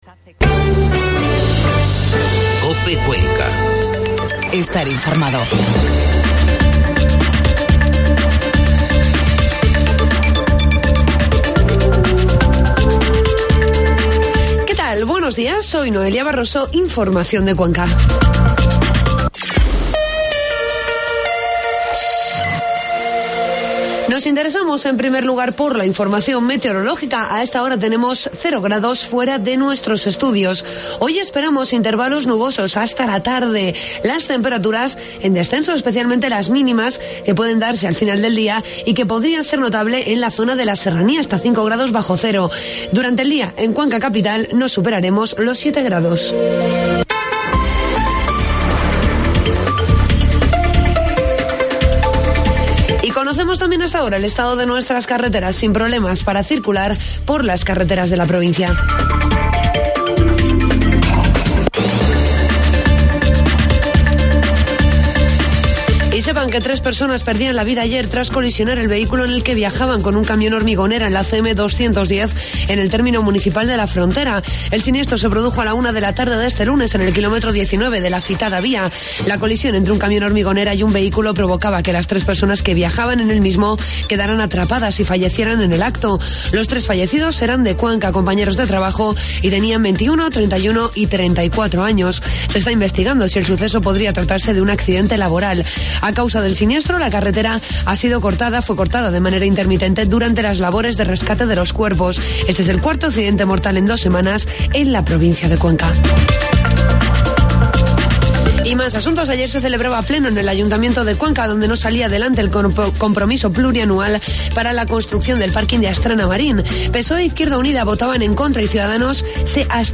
AUDIO: Informativo matinal